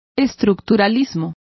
Also find out how estructuralismo is pronounced correctly.